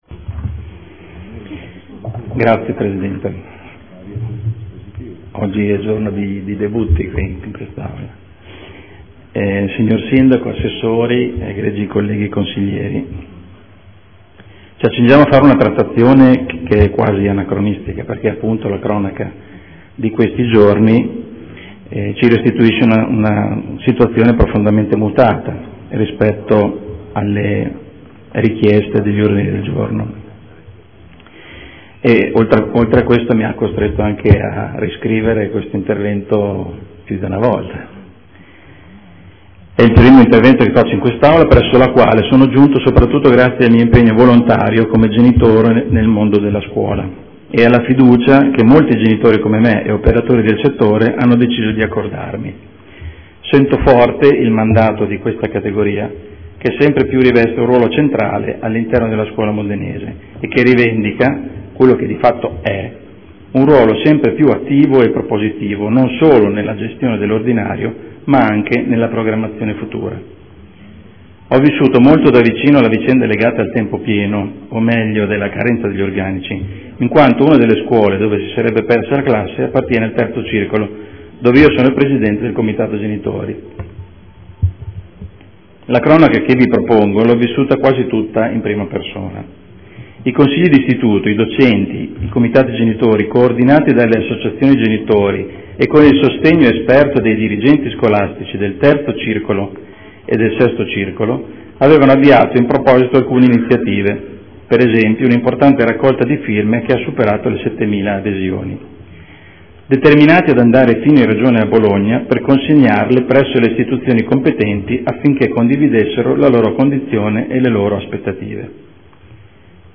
Carmelo De Lillo — Sito Audio Consiglio Comunale